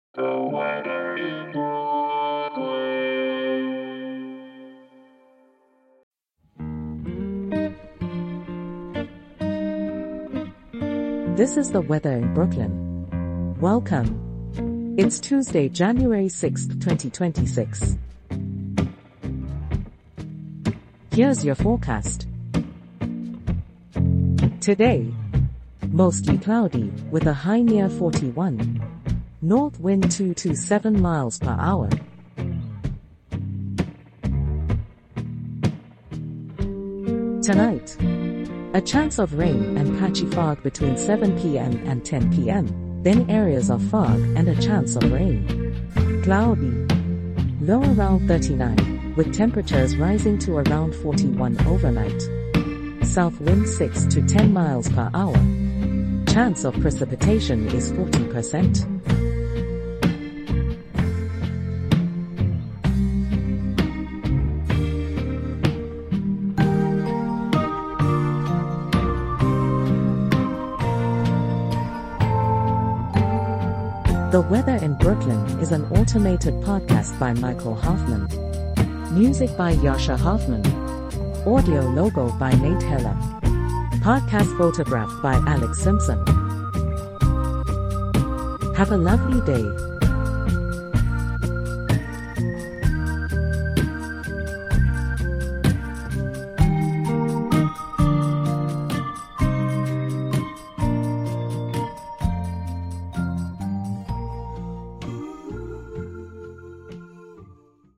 generated automatically